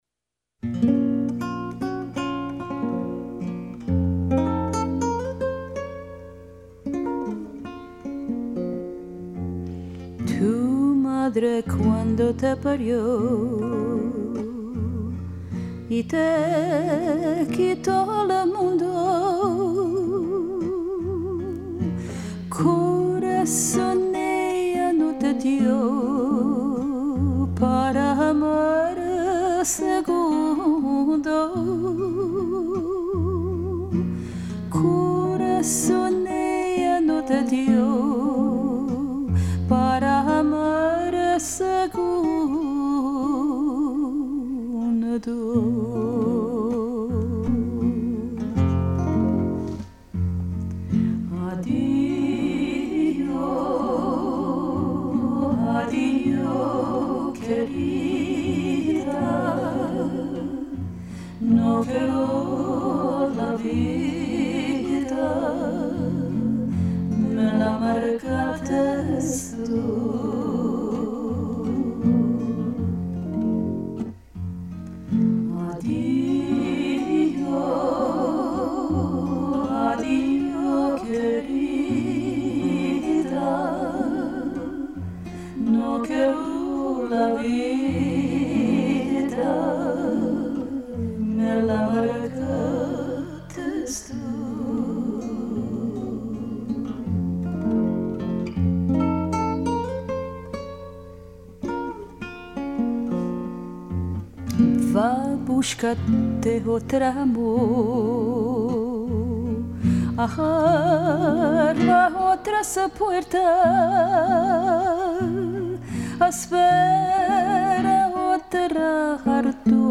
If you listen to the Sarajevo-born master folksinger’s rendition of
adijo kerida you may hear  a song of goodbye that could be to a person or to a civilization (see below).